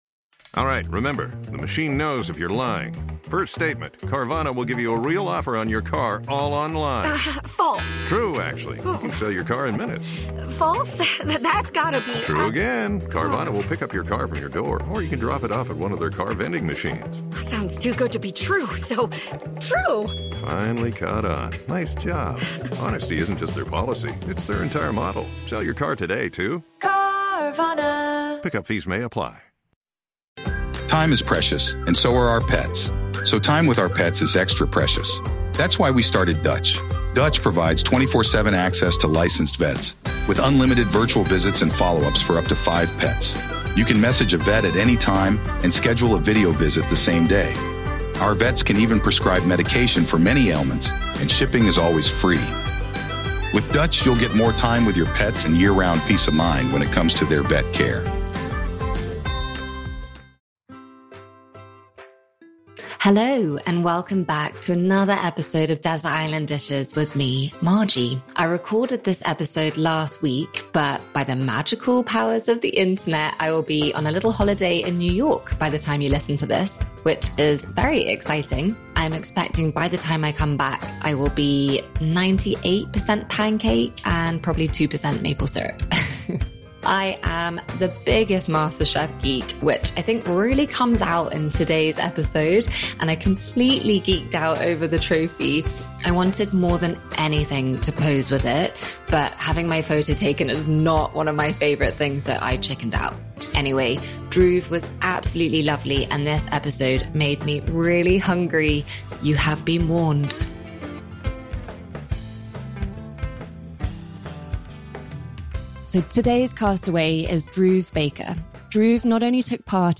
I recorded this episode last week at Dhruv’s beautiful home, but by the magical powers of the internet I will be on a little holiday in New York by the time you listen to this or read this, which is very exciting.